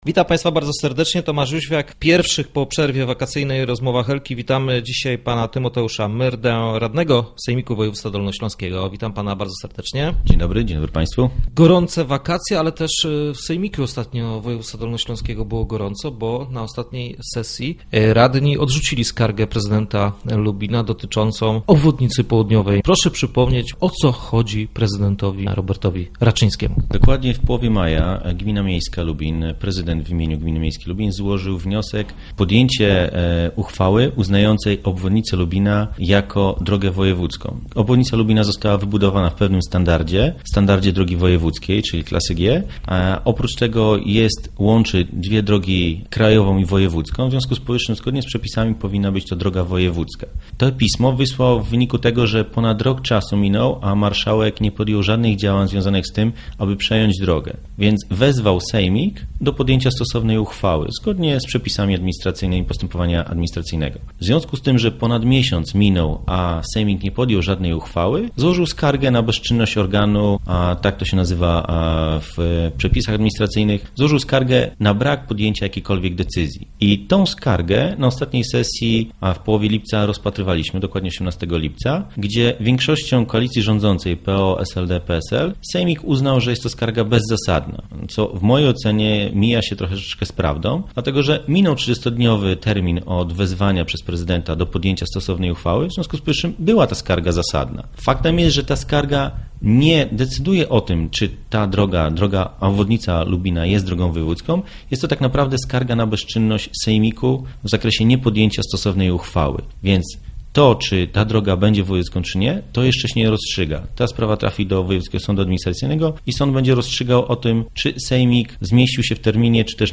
Na ostatniej sesji radni sejmiku odrzucili skargę prezydenta, uznając ją za bezzasadną. Racji gospodarza Lubina broni Tymoteusz Myrda z klubu radnych Rafała Dutkiewicza - Obywatelski Dolny Śląsk.